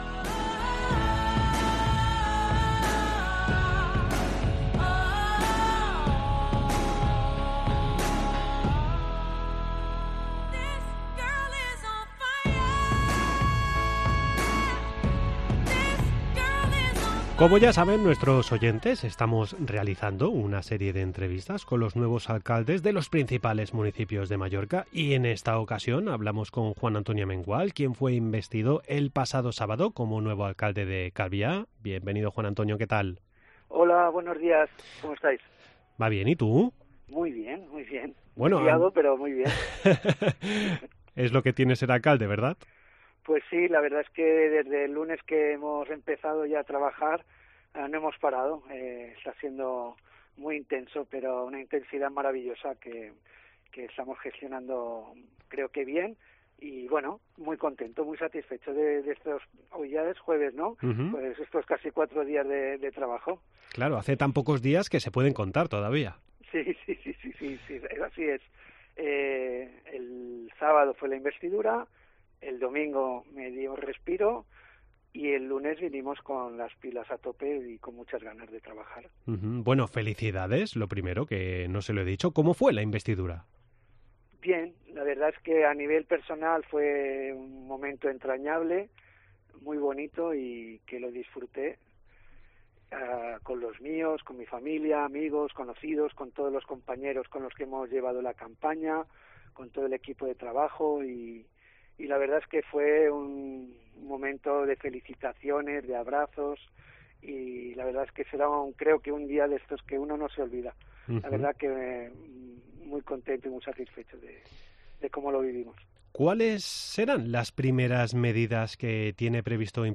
AUDIO: Charlamos con el recién electo alcalde de Calviá, Juan Antonio Amengual del Partido Popular.
Como ya saben nuestros oyentes estamos realizando una serie de entrevistas con los nuevos alcaldes de los principales municipios.